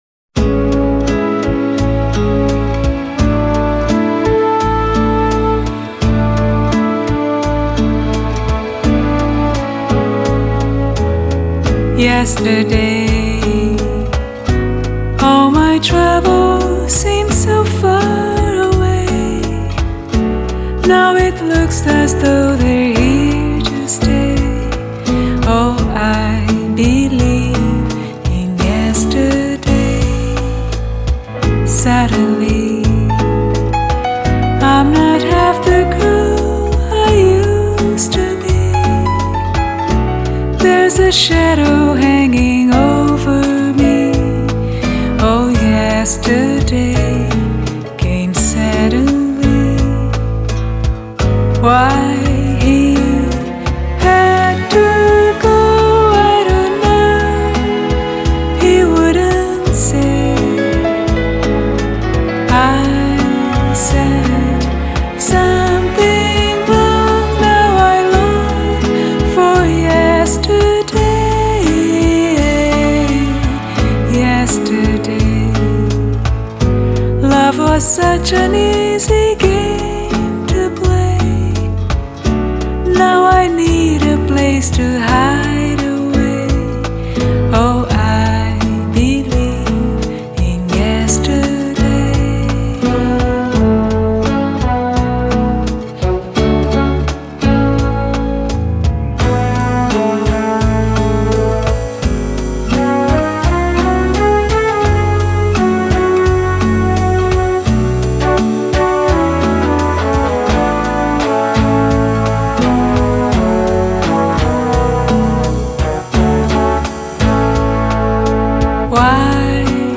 音樂類型 : 爵士樂  Bossa Nova[center]